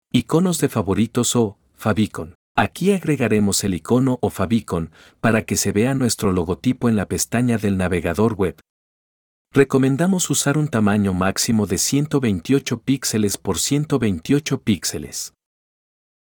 AUDIO TUTORIAL